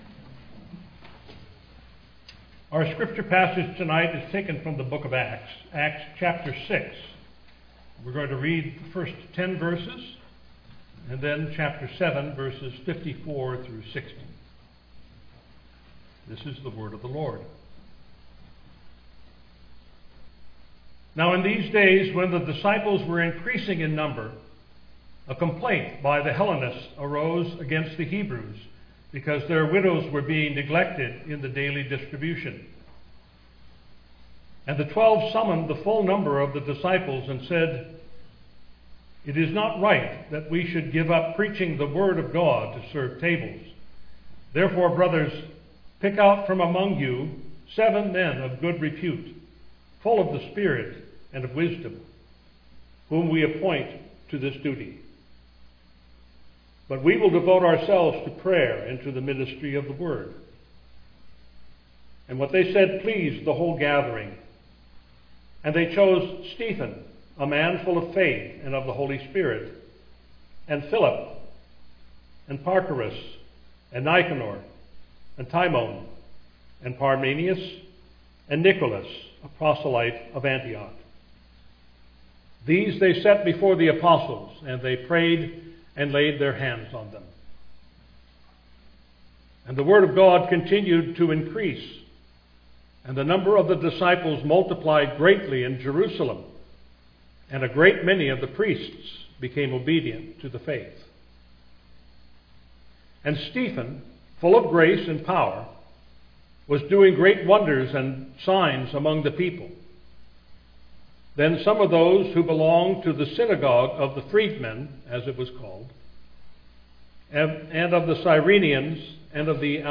Text for Sermon: Acts 6:1-10; 7:54-60